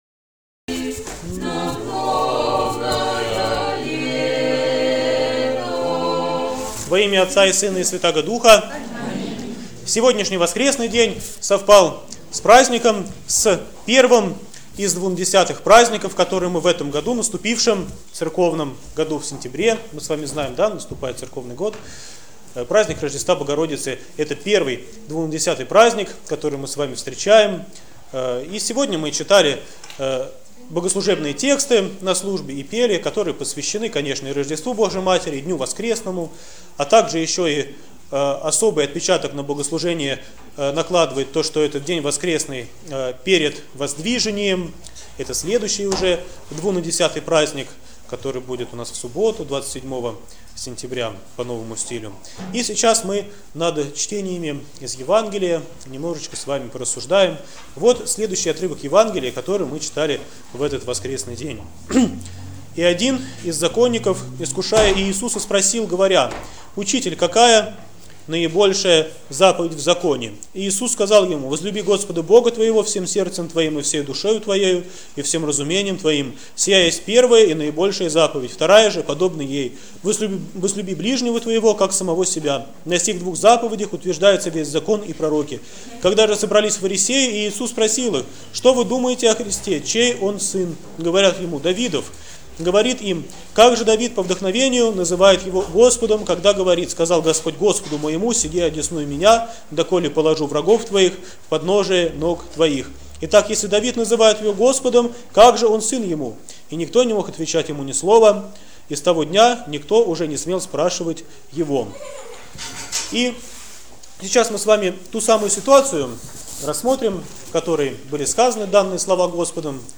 ПРОПОВЕДЬ В НЕДЕЛЮ 15-Ю ПО ПЯТИДЕСЯТНИЦЕ, РОЖДЕСТВО БОГОРОДИЦЫ